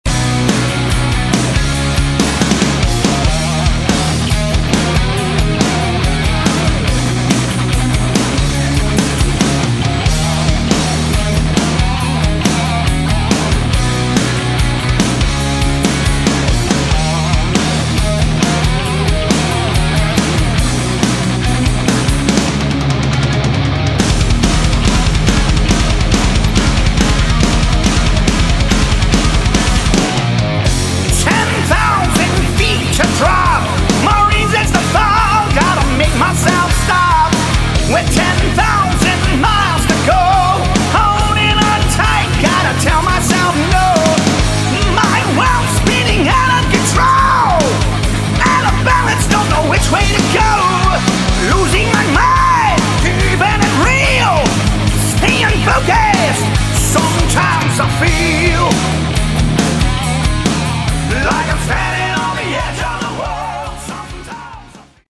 Category: Hard Rock
Lead Vocals
Lead Guitar, Vocals
Drums
Bass Guitar
Good hard rock with an excellent sound.